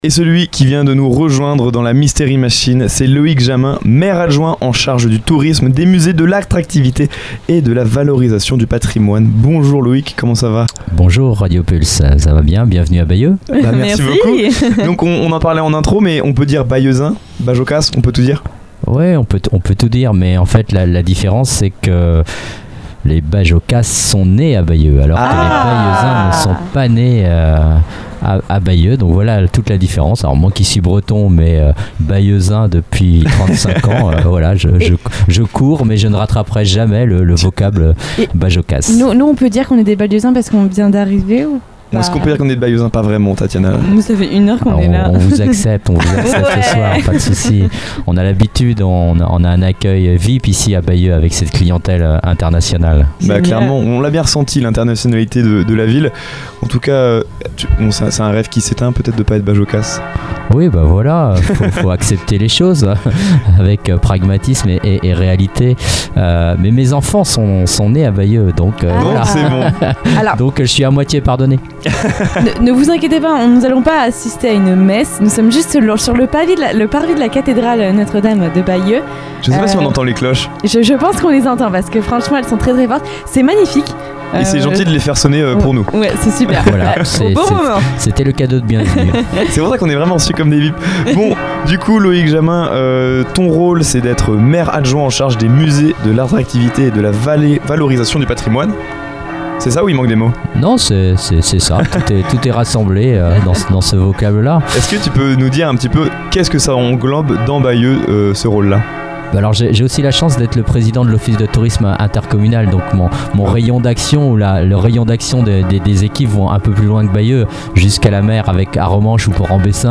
Une interview passionnante qui met en lumière une ville emblématique de Normandie, entre patrimoine, culture et dynamisme touristique, dans l’esprit du Mystery Machine Summer Tour.